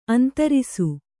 ♪ antarisu